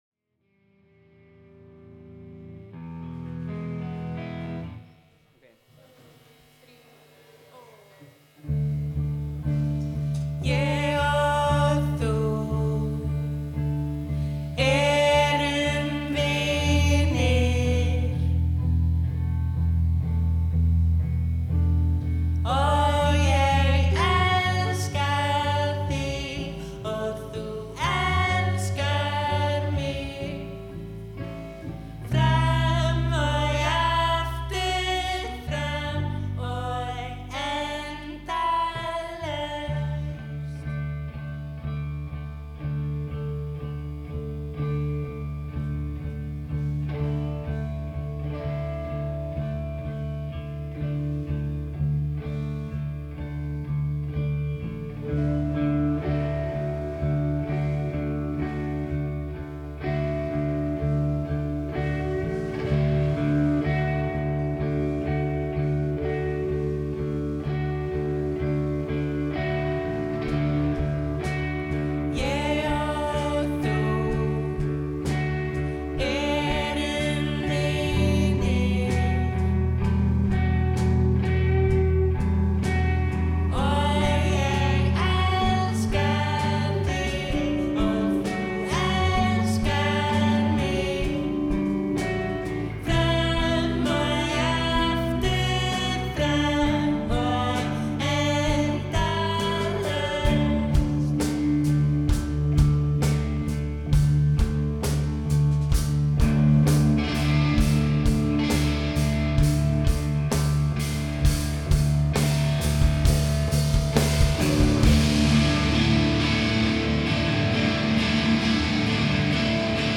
recorded at Eurosonic Festival in Groningen
artsy indie pop
is sweet and playful
singing together, harmonizing
guitar